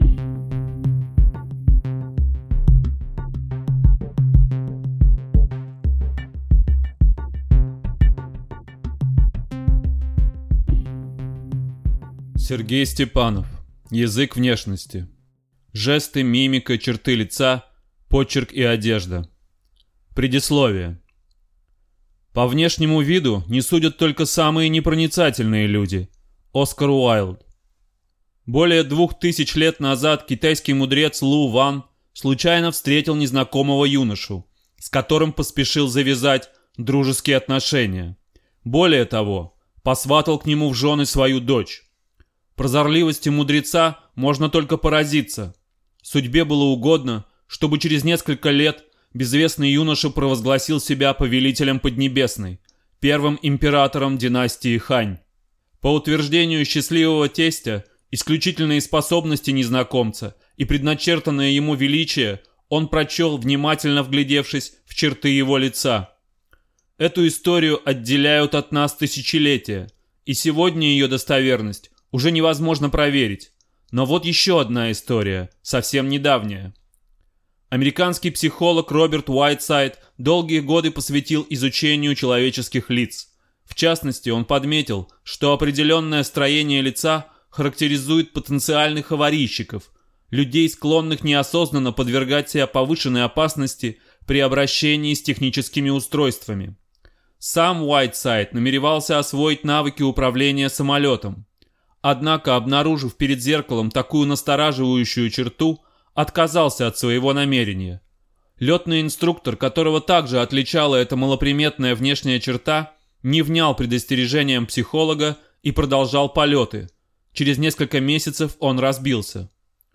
Аудиокнига Язык внешности. Жесты, мимика, черты лица, почерк и одежда | Библиотека аудиокниг